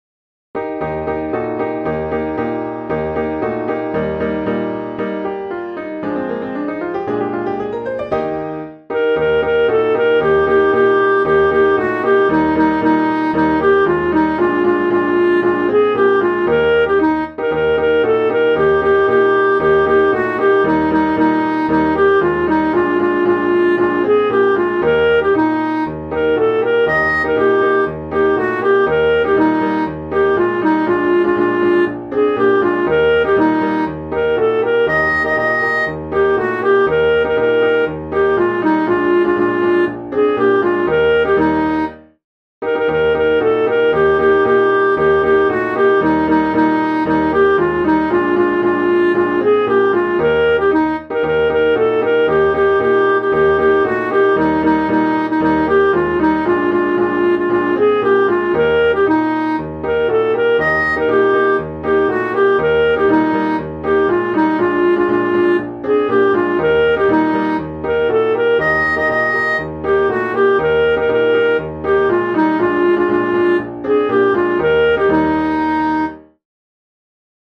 Music Hall Songs: